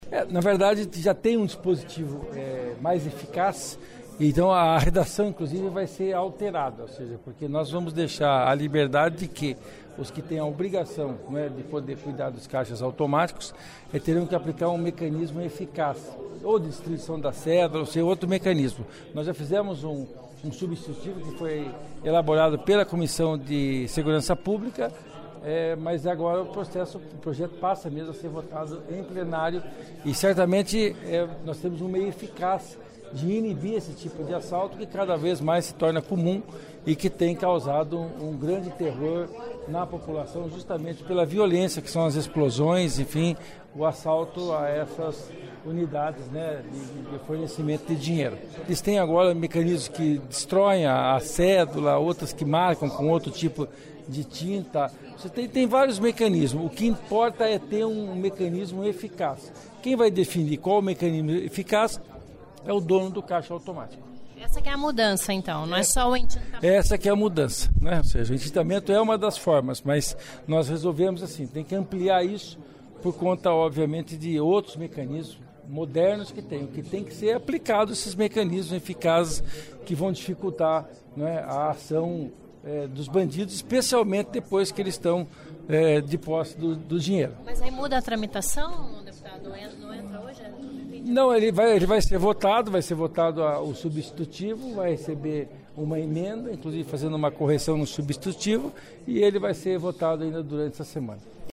Ouça entrevista com líder do Governo, autor do projeto, o deputado Luiz Cláudio Romanelli (PSB) sobre as mudanças no projeto original.